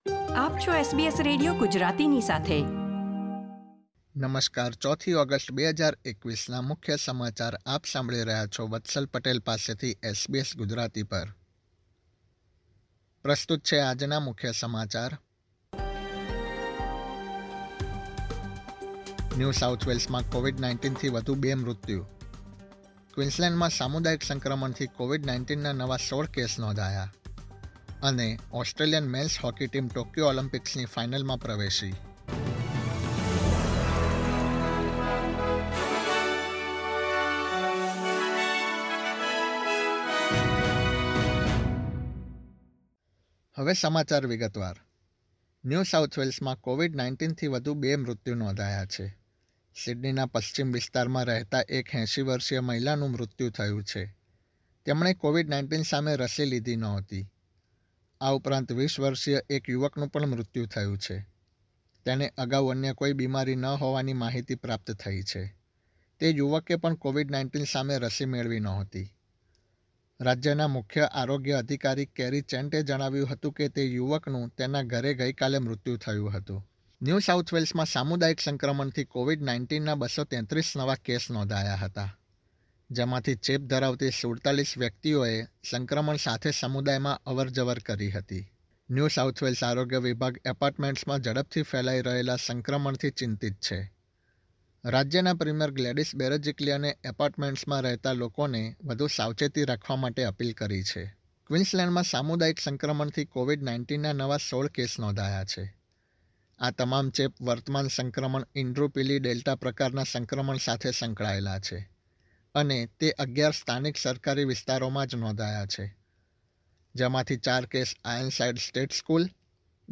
SBS Gujarati News Bulletin 4 August 2021
gujarati_0408_newsbulletin.mp3